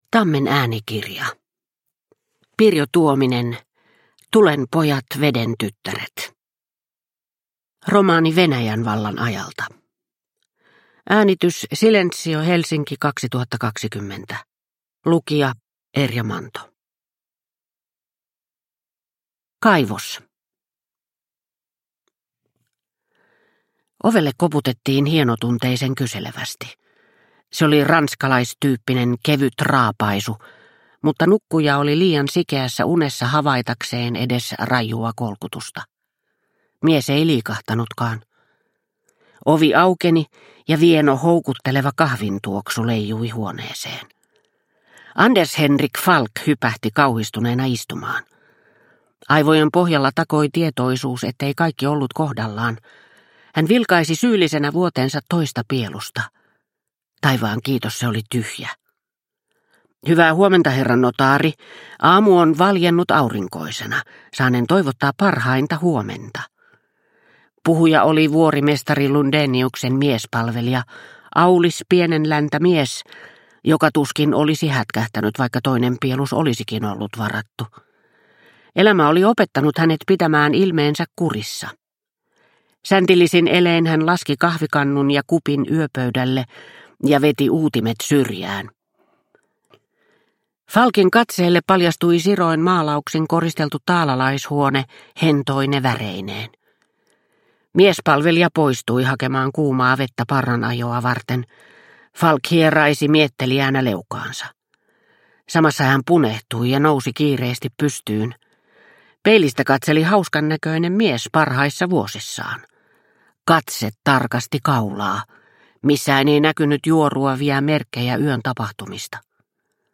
Tulen pojat, veden tyttäret – Ljudbok – Laddas ner